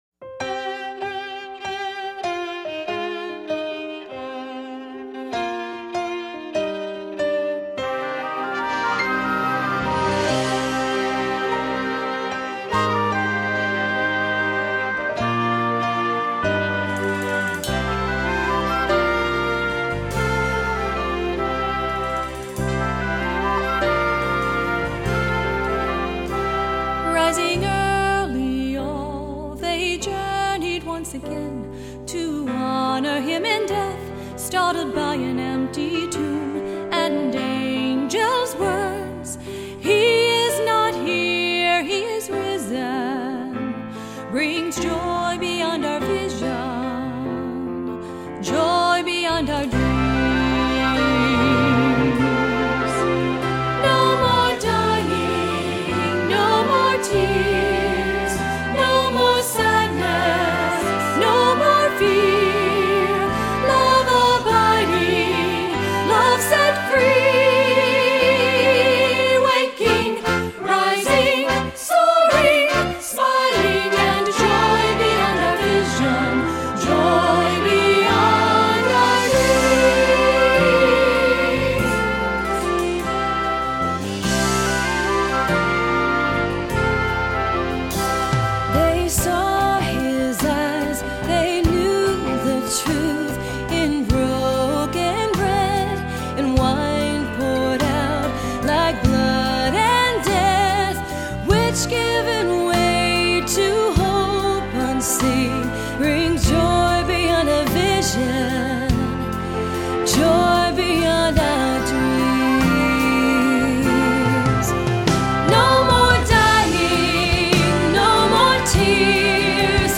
Voicing: SATB, cantor, descant, assembly